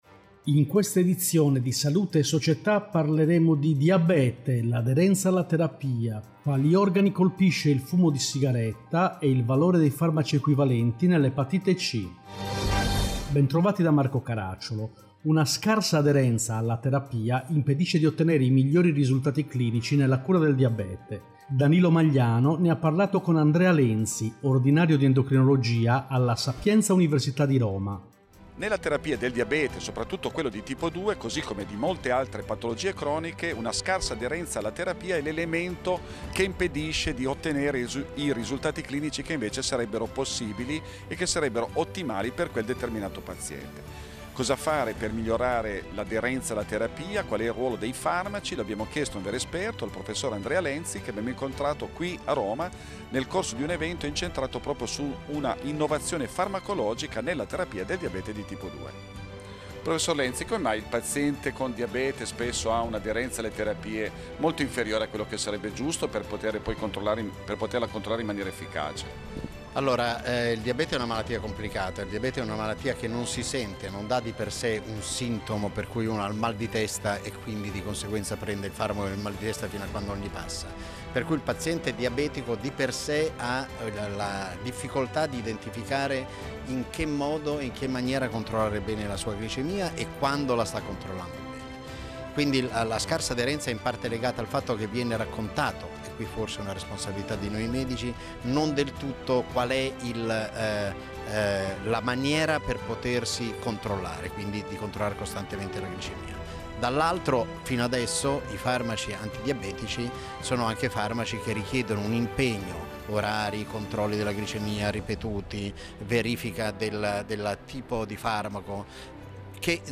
In questa edizione: 1. Diabete, Aderenza alla terapia 2. Fumo di sigaretta, Quali organi colpisce 3. Epatite C, I farmaci equivalenti Interviste